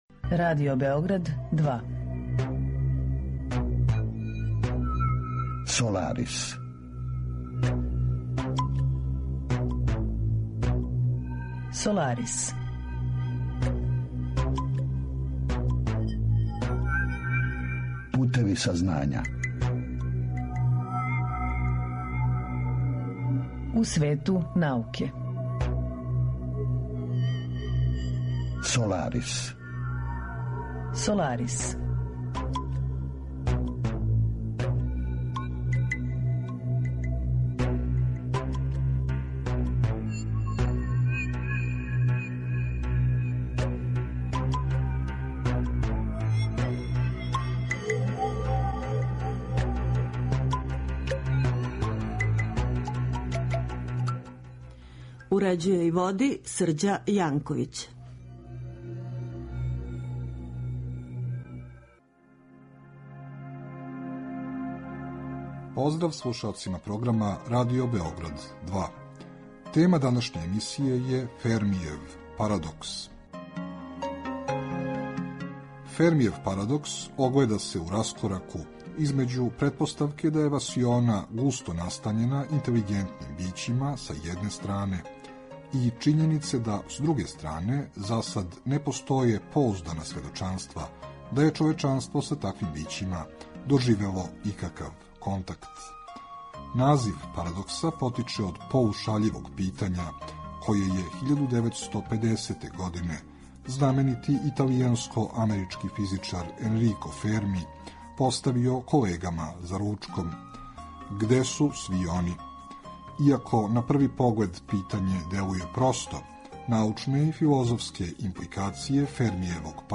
Разговор је први пут емитован 21. јануара 2018.